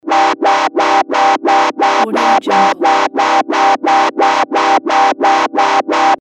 دانلود افکت صدای آژیر خطر سریع
Sample rate 16-Bit Stereo, 44.1 kHz
Looped Yes